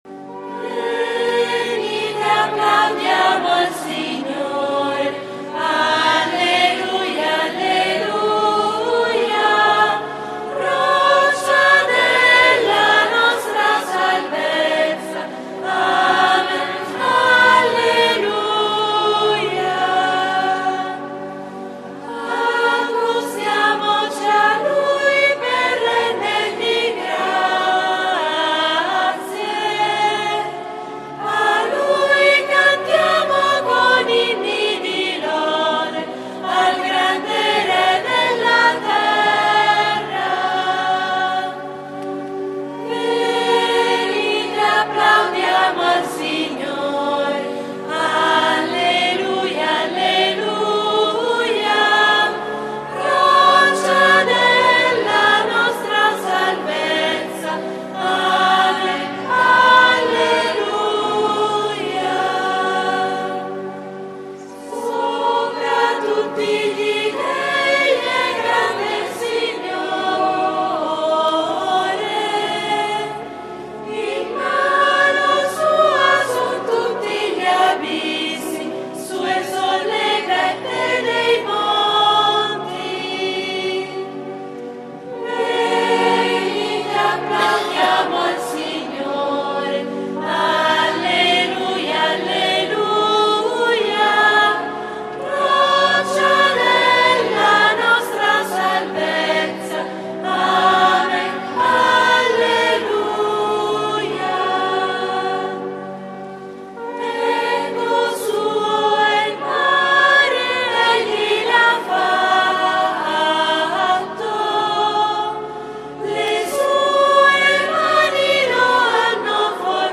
I domenica di AVVENTO - Prima tappa itinerario di Avvento
canto: